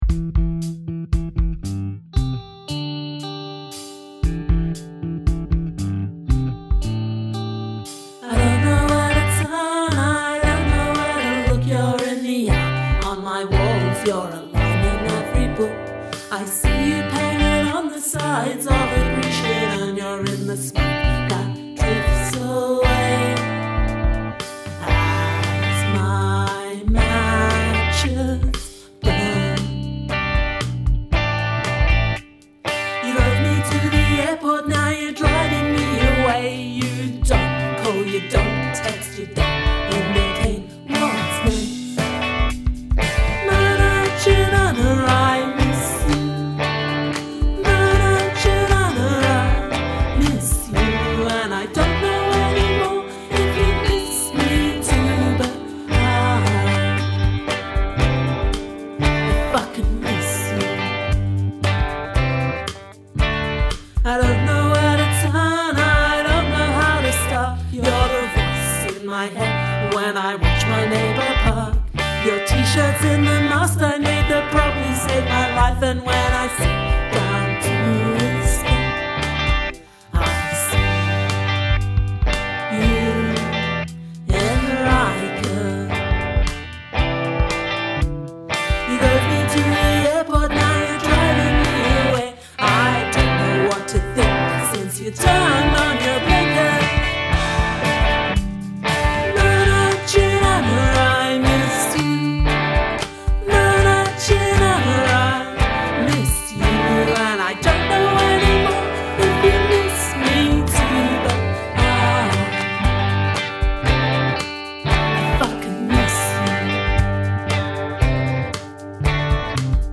Use of field recording
Nice guitaring.
Good field recording usage.
Doubled vox is a good choice.